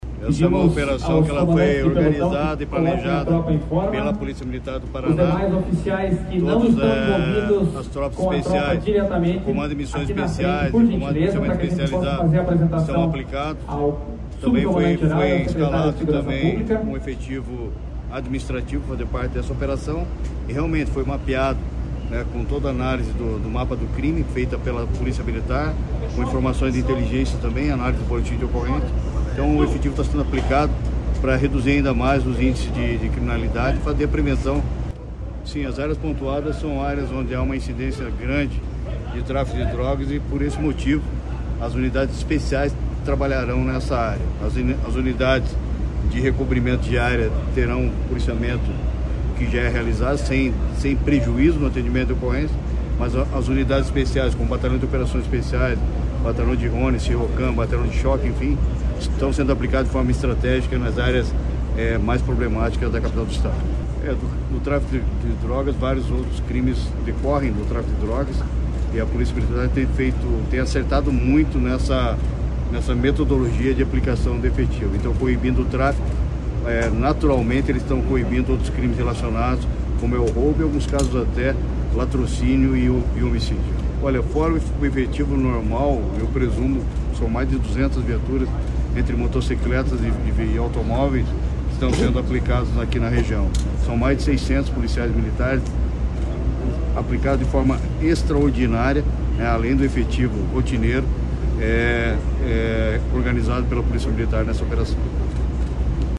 Sonora do secretário Estadual da Segurança Pública, Hudson Teixeira, sobre a Operação Omnis | Governo do Estado do Paraná